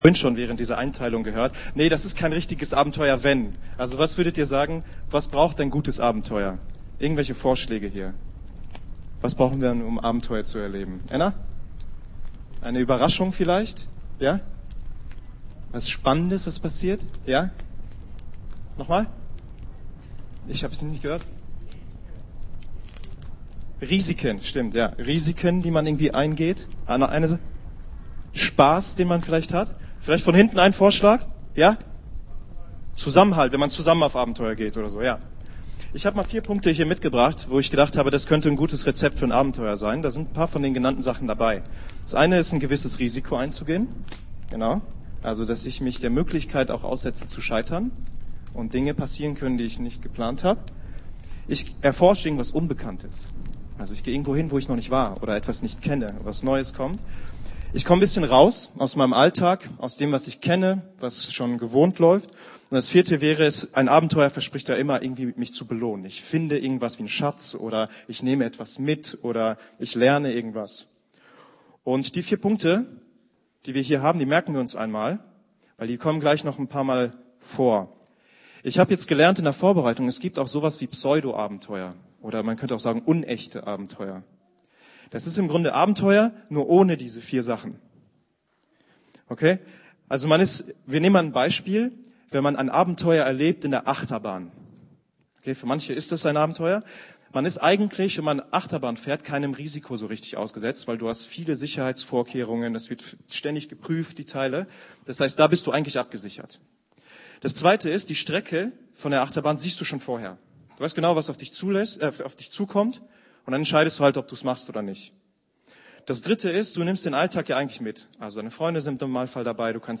Jugendgottesdienst , Predigt Sie sehen gerade einen Platzhalterinhalt von YouTube .